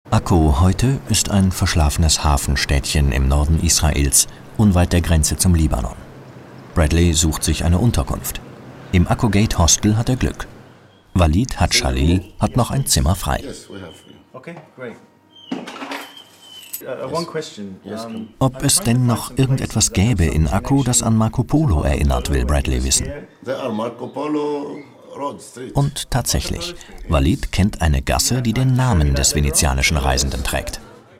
Male
Assured, Authoritative, Confident, Corporate, Engaging, Friendly, Natural, Reassuring, Warm
Entertainment Reel.mp3
Microphone: Brauner VM 1, AKG414 B